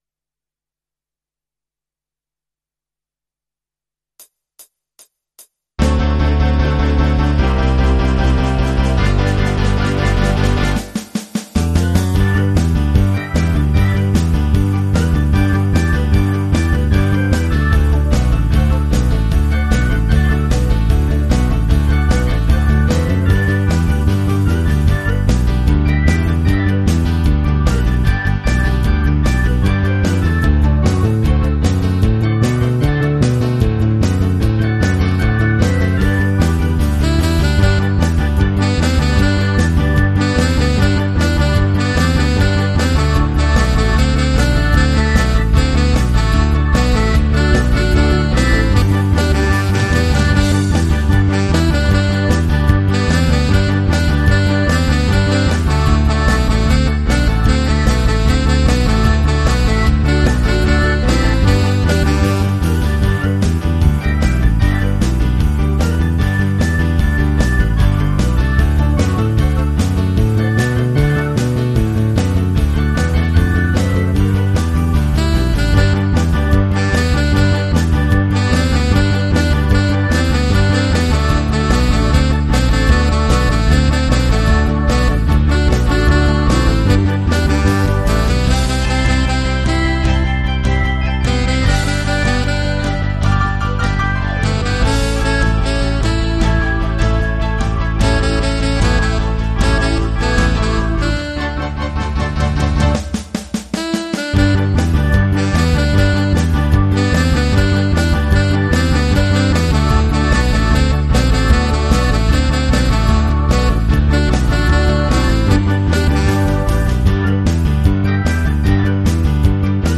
mehrspurige Instrumentalversion des Songs